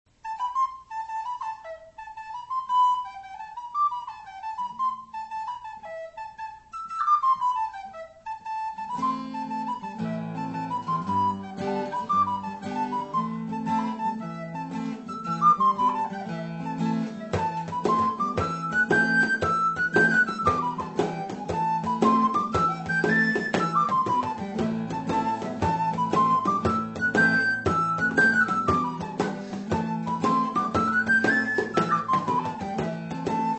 Soprano Recorder
Guitar
Bodhran